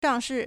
上市 shàngshì
shang4shi4.mp3